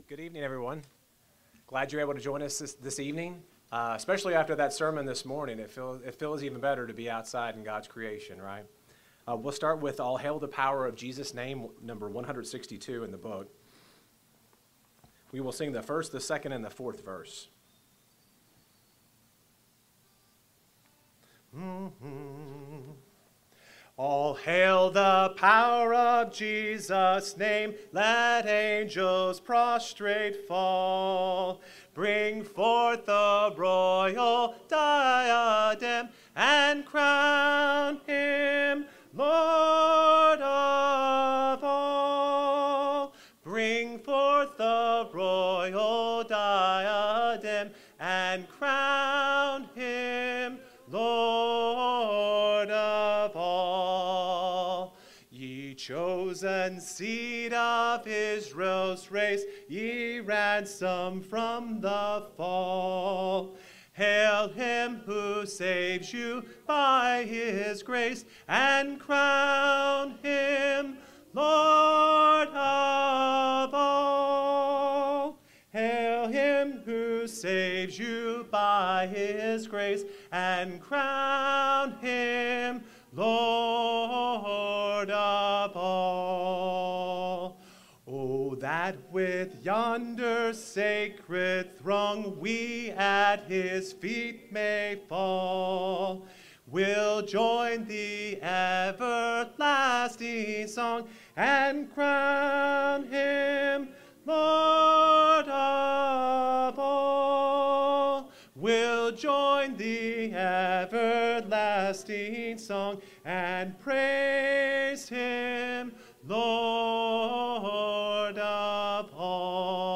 Luke 15:10, English Standard Version Series: Sunday PM Service